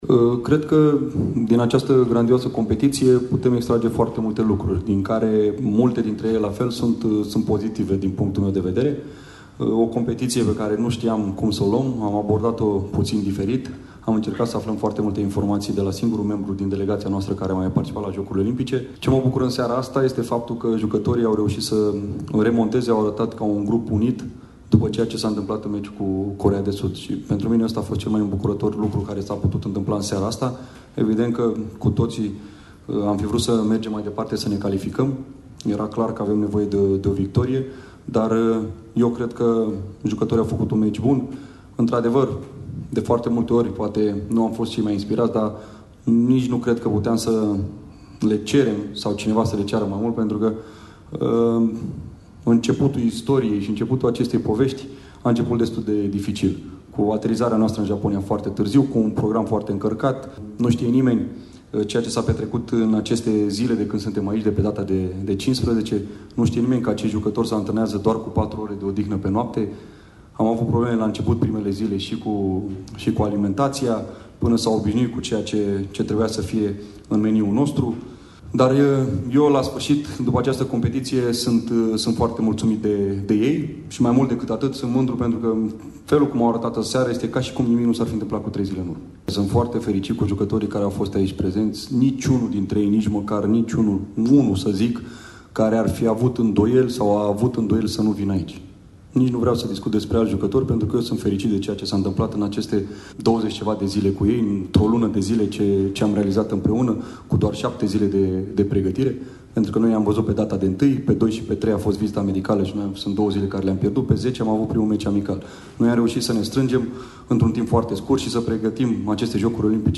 Concluziile turneului au fost formulate de selecționerul Mirel Rădoi: